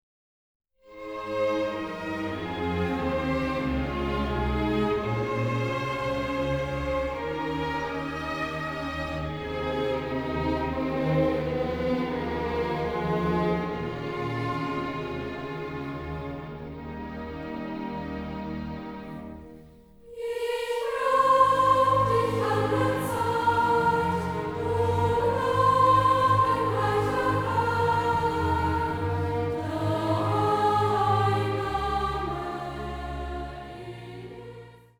gemischter Chor, Orchester